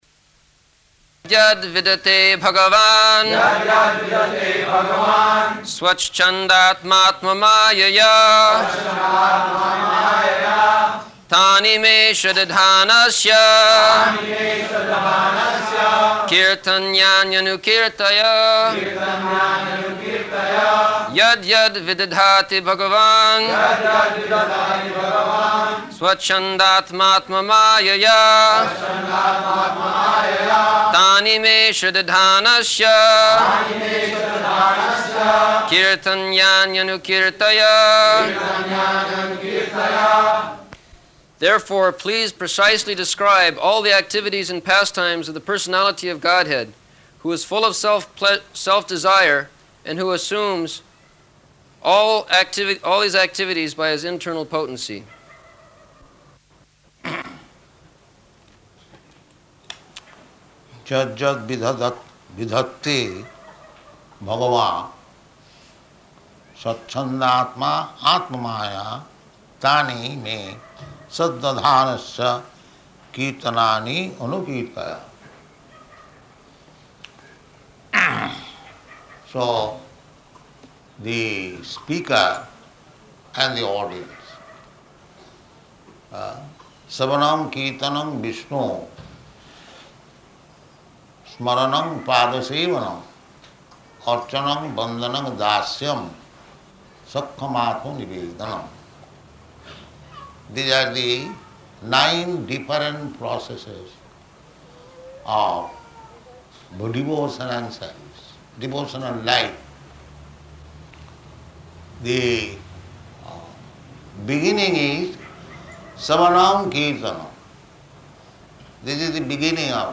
November 3rd 1974 Location: Bombay Audio file
[devotees repeat]
[buzzing sound] ...was recited by Śukadeva Gosvāmī, and it was heard by Parīkṣit Mahārāja.